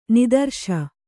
♪ nidarśa